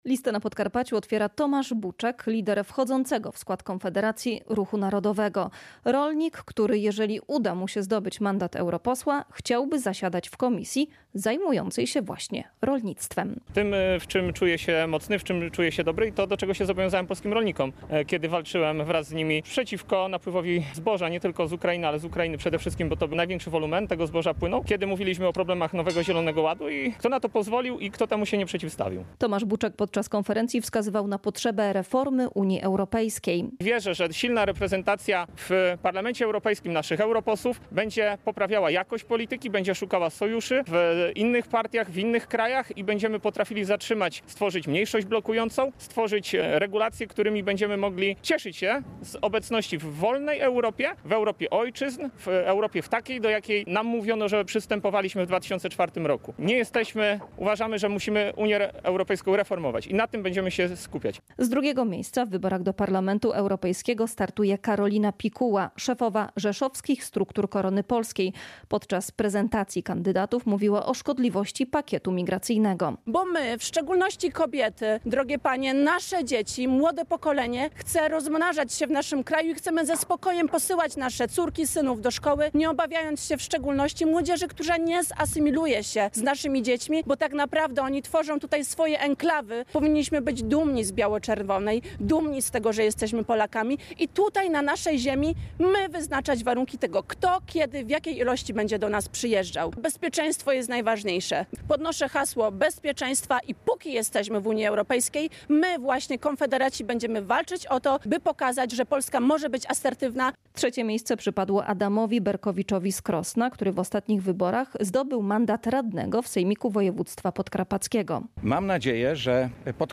W Rzeszowie poseł Grzegorz Braun przedstawił niektórych kandydatów do europarlamentu, zapowiedział cykl konferencji w tej kampanii wyborczej, intensywne pięć tygodni pracy i wsparcie kandydatów.